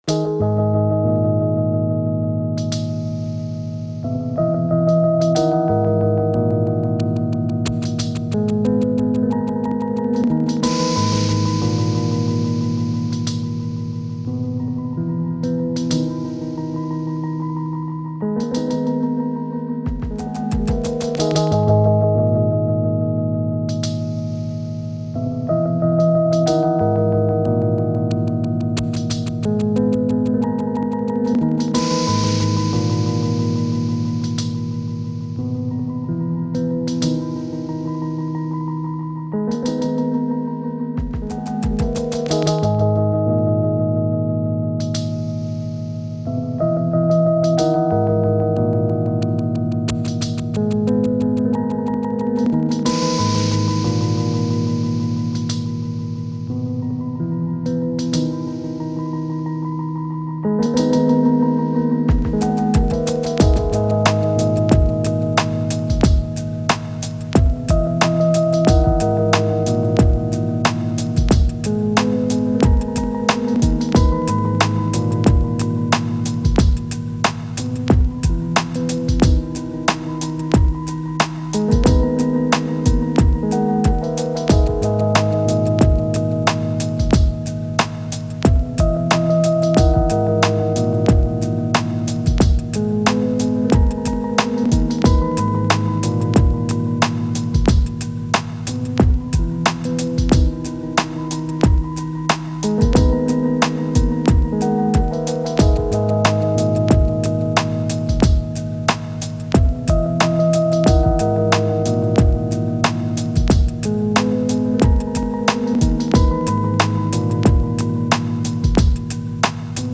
Dark reflective thoughtful trip hop.